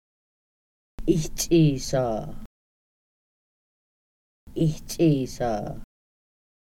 Individual audio recordings of Kaska words and phrases about the seasons. This subset of the original Seasons and Weather Deck focuses on springtime.